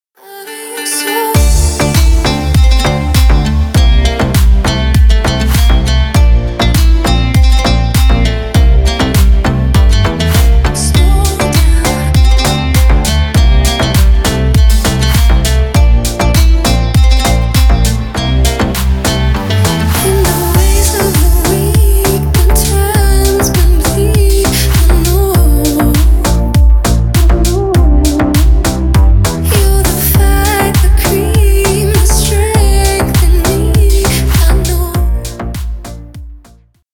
Танцевальные
восточные